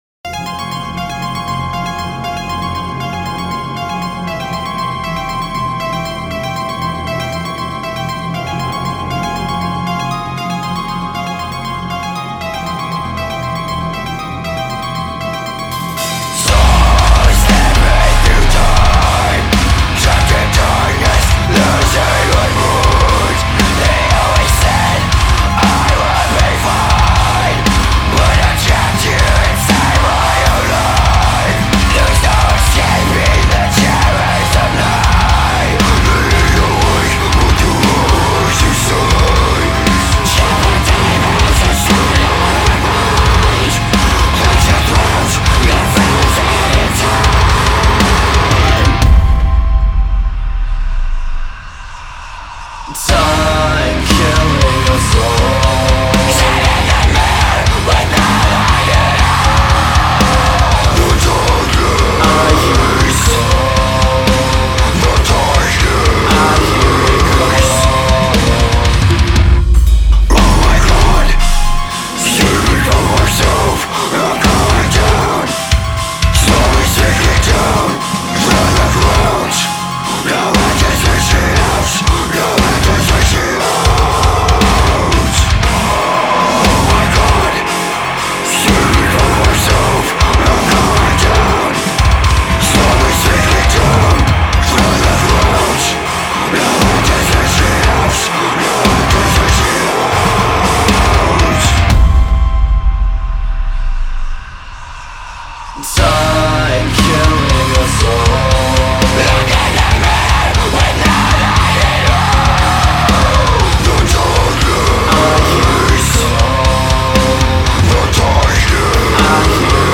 Vocals
Drums
Guitar & Synth